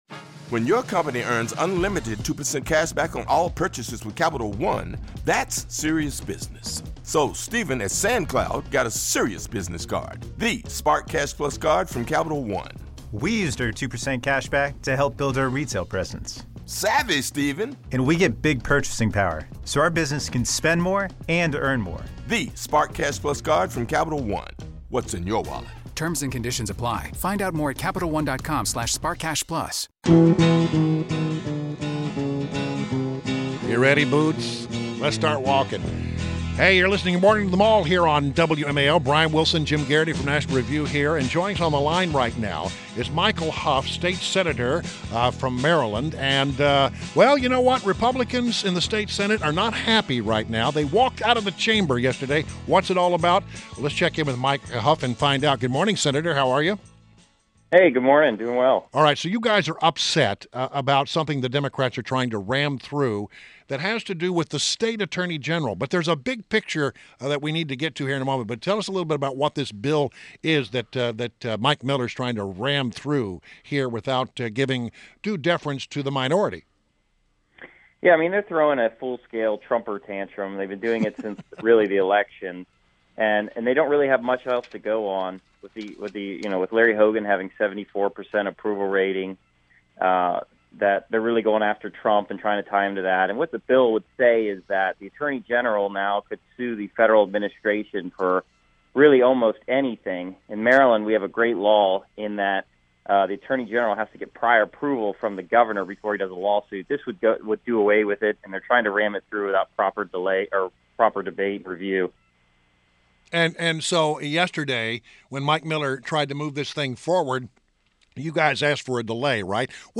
INTERVIEW — Maryland State Senator MICHAEL HOUGH (pronounced HUFF) — representing Frederick & Carroll Co.